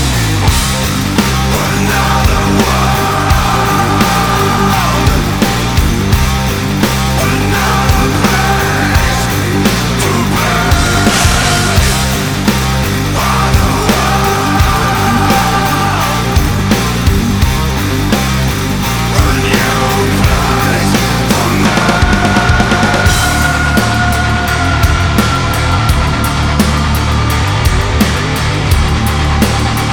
• Metal